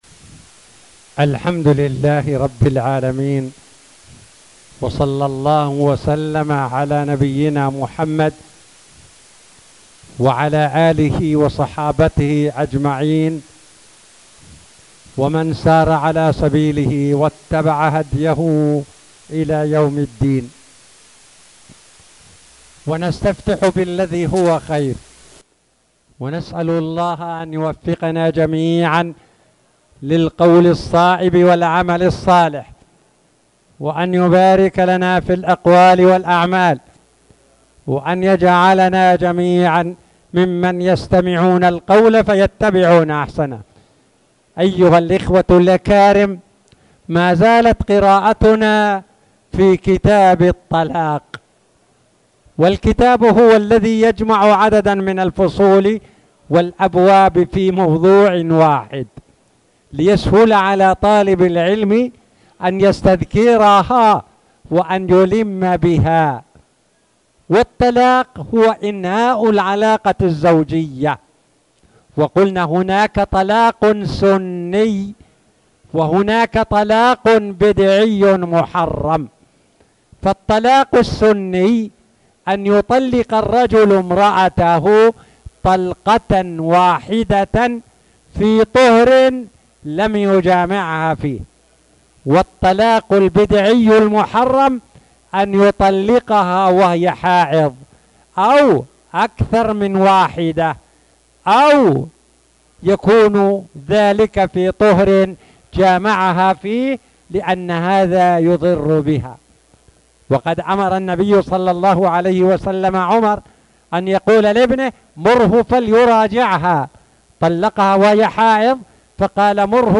تاريخ النشر ٧ رجب ١٤٣٨ هـ المكان: المسجد الحرام الشيخ